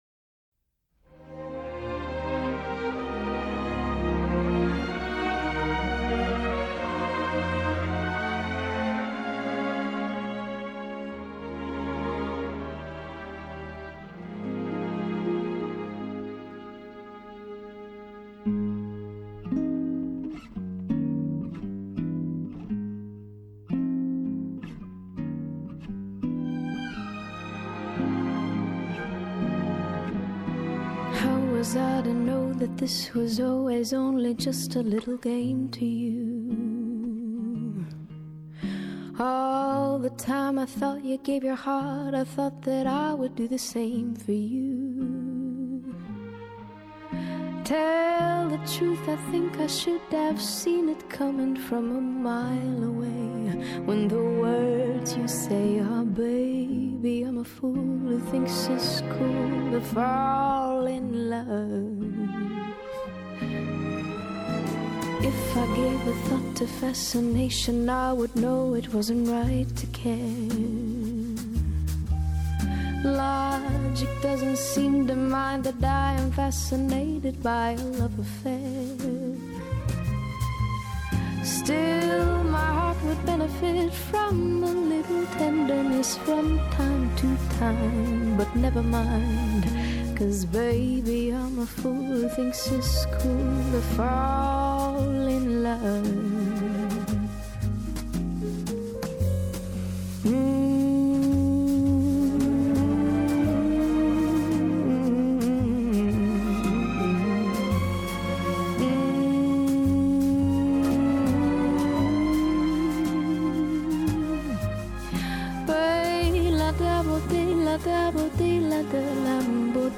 Jazz Vocal, Pop